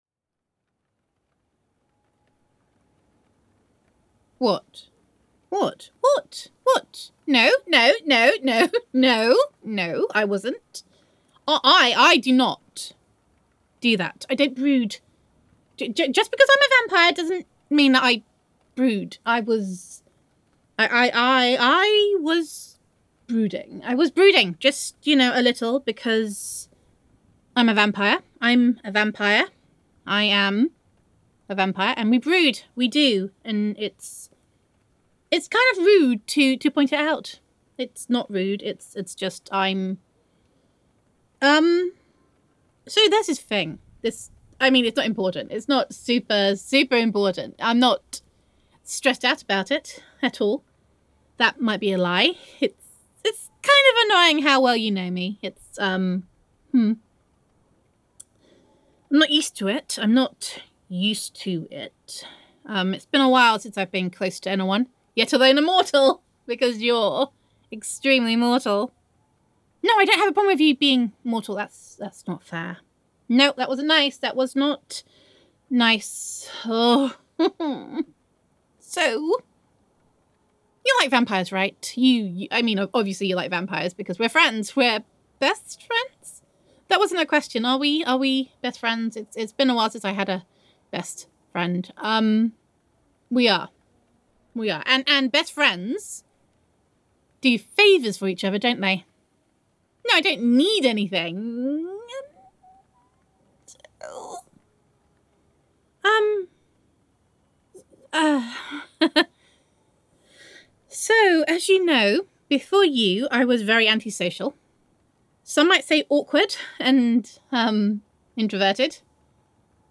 [F4A]
[Vampire Roleplay]